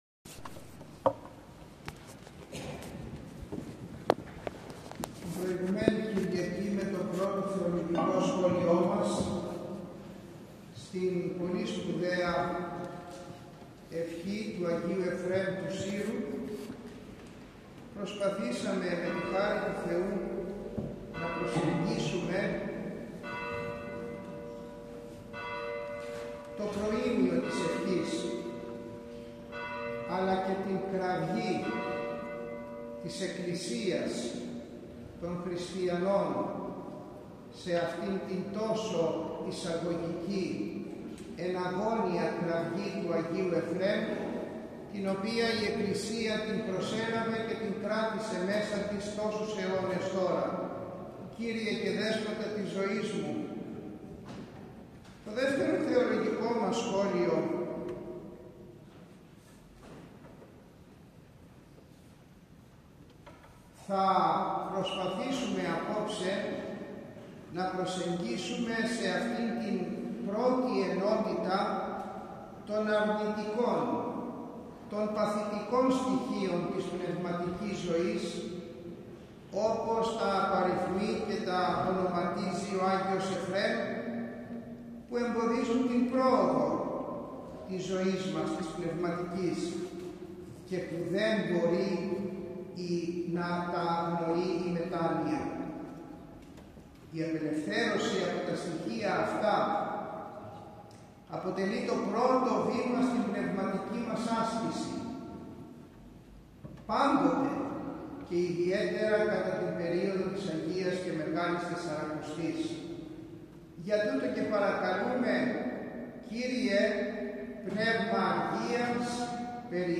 Ο Σεβασμιώτατος Μητροπολίτης Θεσσαλιώτιδος και Φαναριοφερσάλων κ. Τιμόθεος, το εσπέρας της Κυριακής 9 Μαρτίου χοροστάτησε στην ακολουθία του Β΄ Κατανυκτικού Εσπερινού στον Ιερό Μητροπολιτικό Ναό Αγίων Κωνσταντίνου και Ελένης Καρδίτσης.